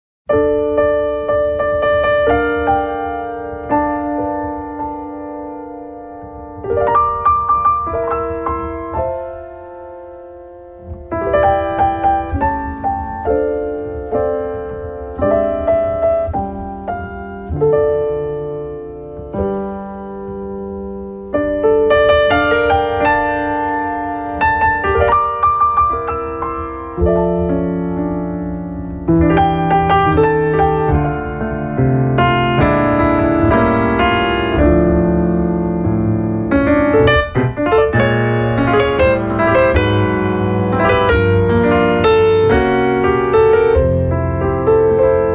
Recorded At Sony Music Studios In Tokyo On January 23, 2013.